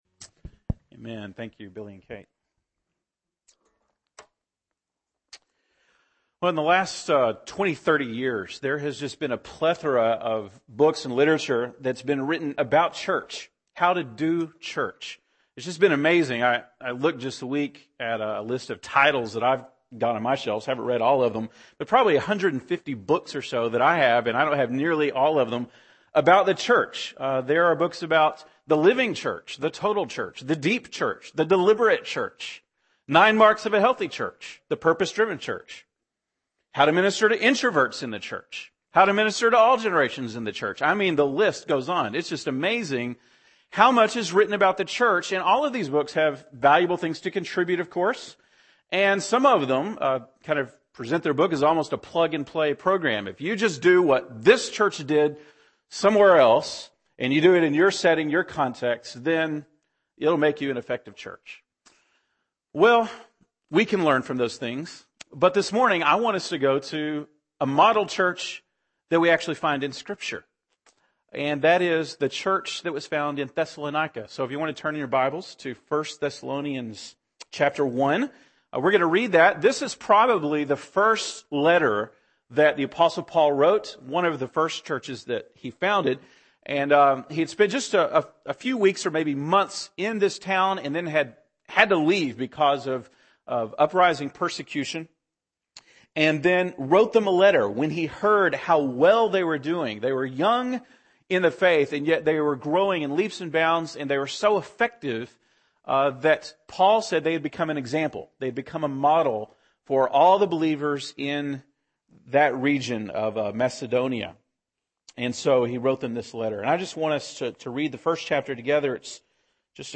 January 8, 2012 (Sunday Morning)